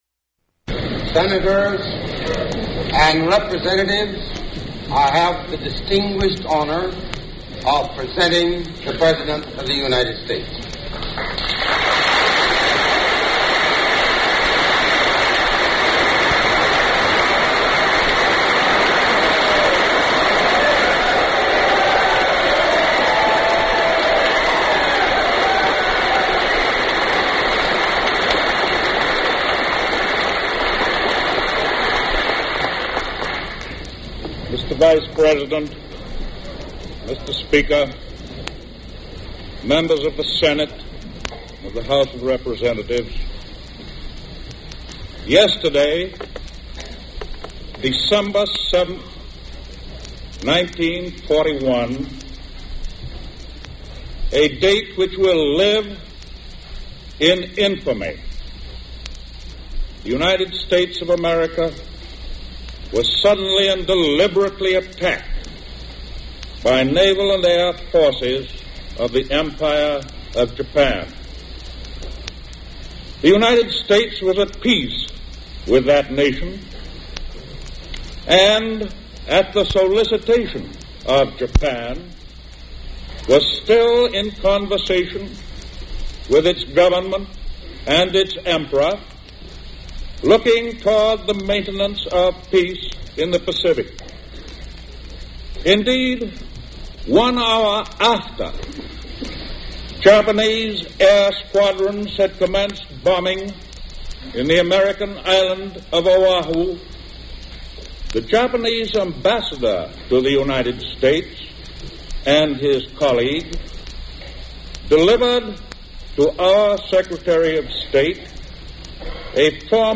U.S. President Franklin D. Roosevelt delivers a speech to a joint session of Congress asking for a declaration of war with Japan : NBC broadcast